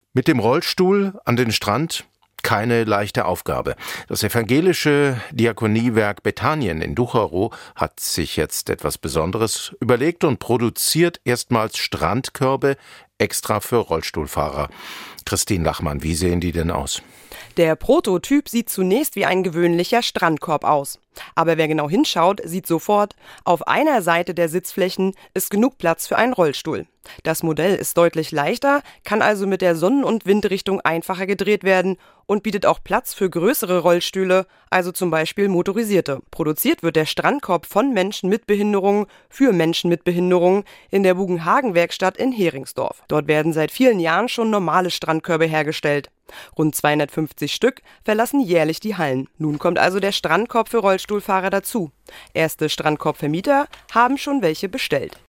Ein MP3-Audio-Mitschnitt vom NDR:
„Die MP3-Sprachversion zum Artikel Heringsdorf: Erstmals Strandkörbe für Rollstuhlfahrer gebaut"